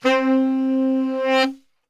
C 2 SAXSWL.wav